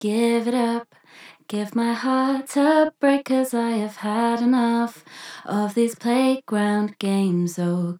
ダブラーというエフェクトは、ボーカルをより際立たせるために薄くかけたり、サビなどの時に他のパートとメリハリをつける為にも使ったりする事が多いです。